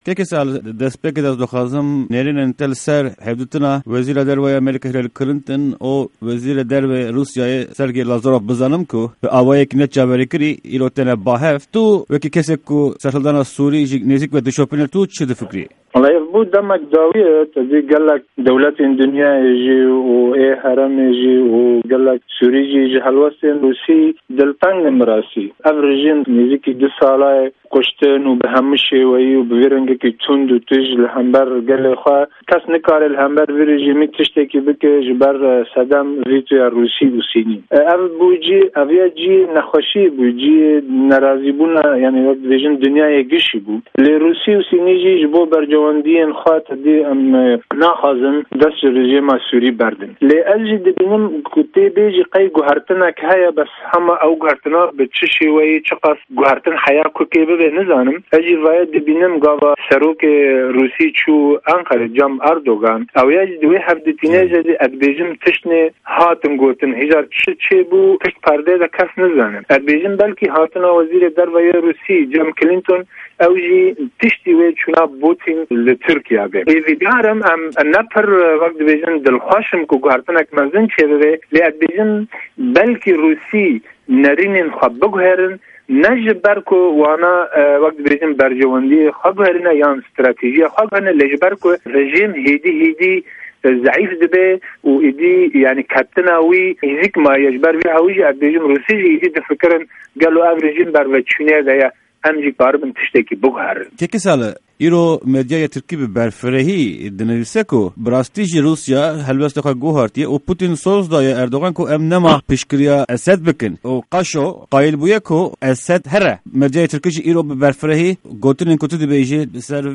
Di hevpeyvîna Pişka Kurdî ya Dengê Amerîkayê de